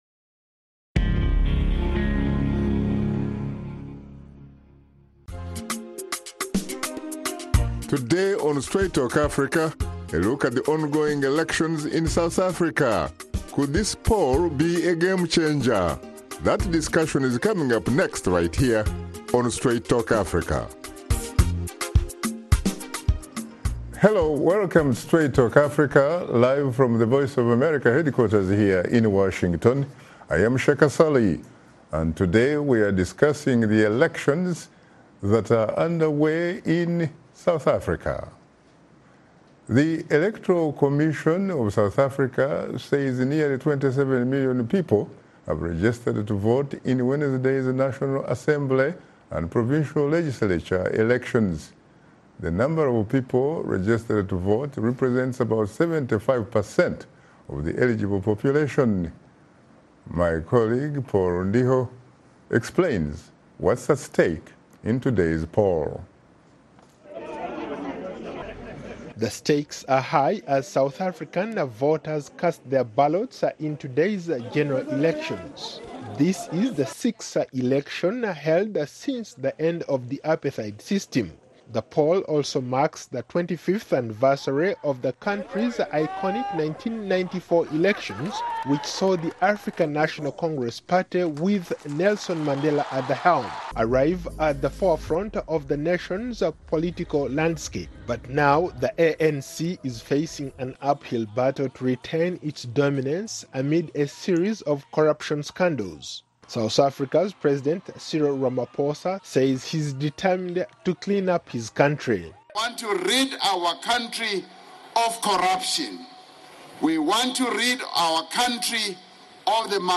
On this fresh, fast-paced show
talk to teens and young adults about Politics,trends, lifestyles, health, entertainment, and other issues touching listeners’ lives